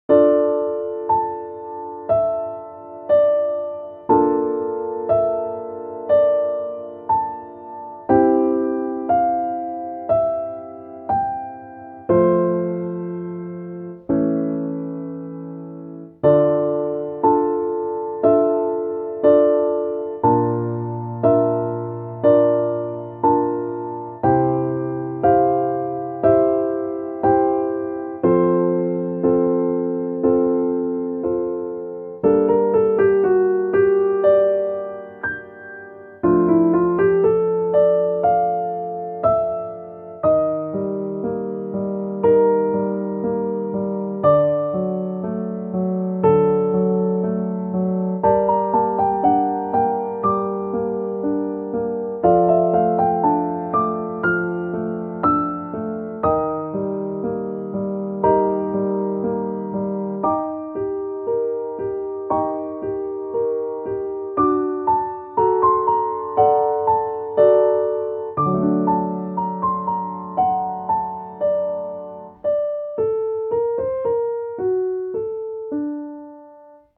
ogg(L) - 不安 寂しさ 迷い